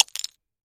На этой странице собраны разнообразные звуки сосулек — от нежного звона капель до резкого обрыва ледяных глыб.